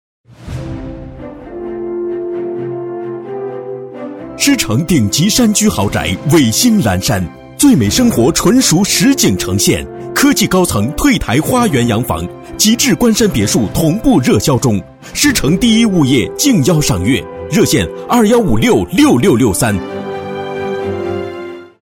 Locución para vender en china
Vender en china a través de una locución narrada con voz masculina que oferta y anuncia chalets de lujo en barrio exclusivo de China.
En este audio podemos apreciar el efecto sonoro creado por una majestuosa música dedicada a satisfacer al cliente más exclusivo.
Locutor-varón-chino-13.mp3